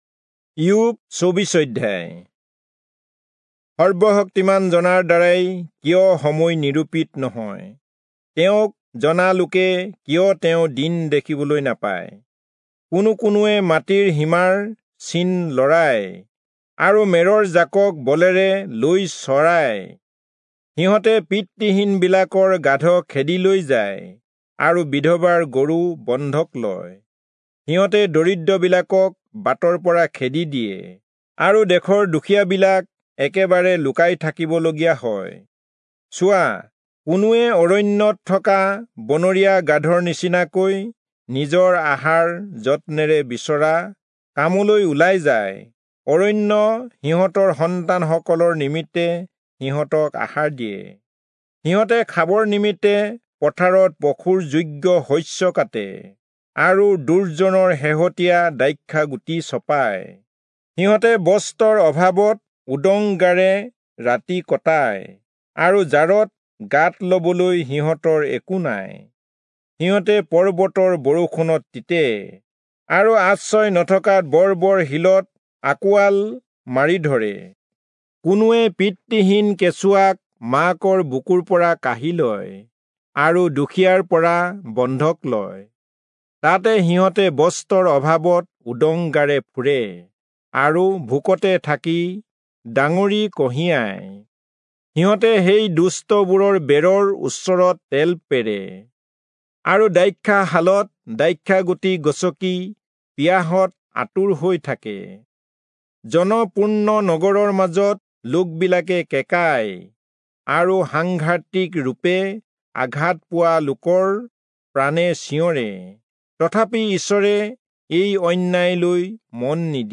Assamese Audio Bible - Job 1 in Bnv bible version